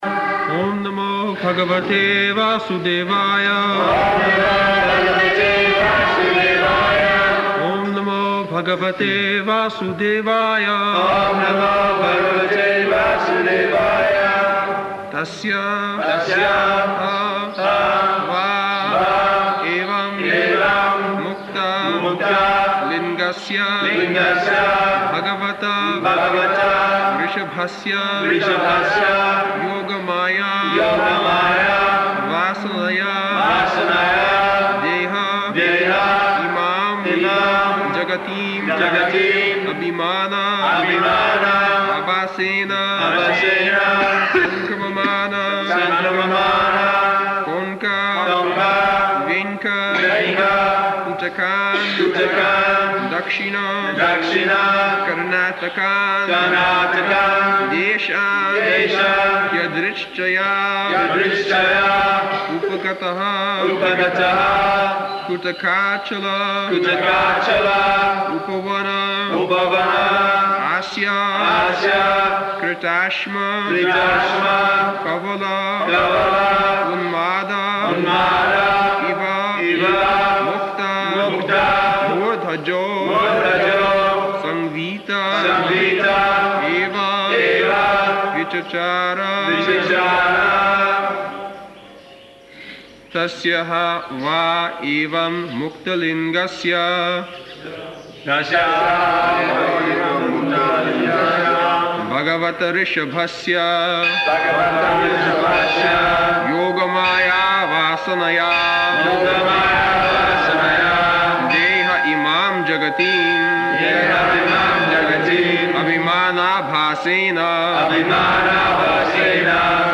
November 29th 1976 Location: Vṛndāvana Audio file
[devotees repeat] [leads chanting, etc.]